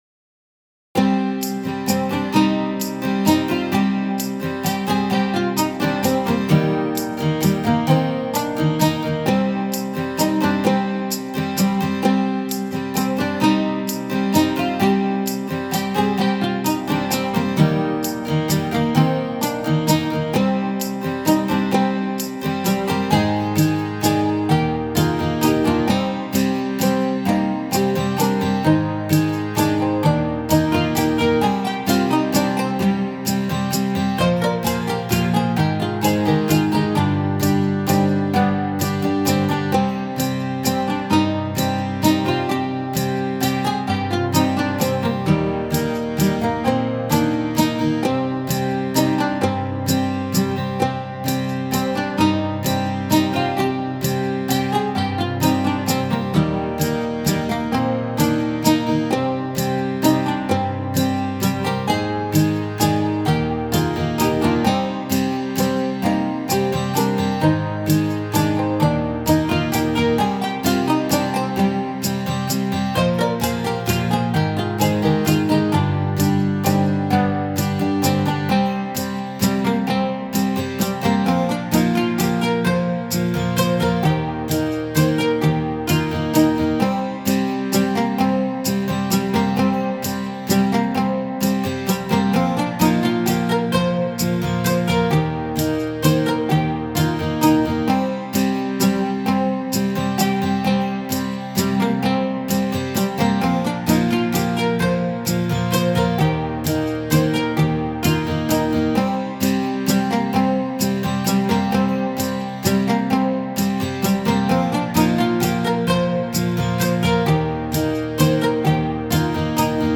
posted a year ago Musician This is some music for a medieval-style game that was never used. It is designed to loop over a large period of time. I tried to keep it intentionally simple so as not to distract the player playing the game. But there are enough sections in the song to keep it interesting enough.